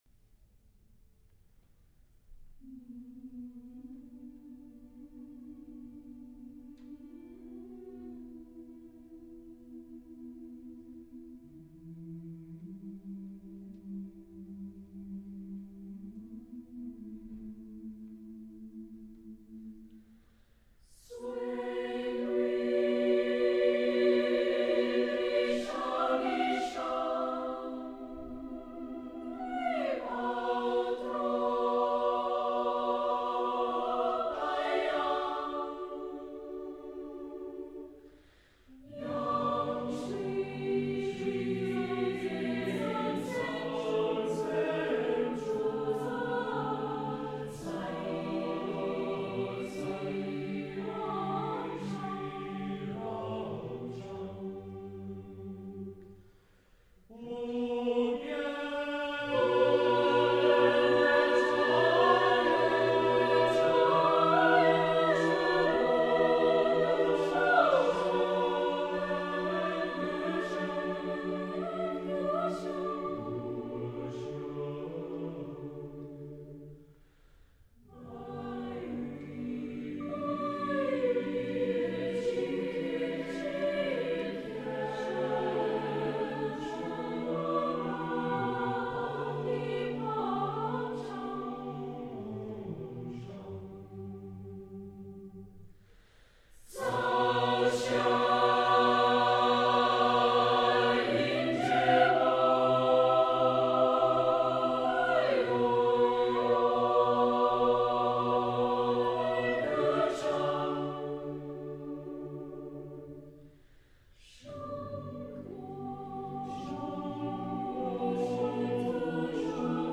Accompaniment:      A Cappella
Music Category:      World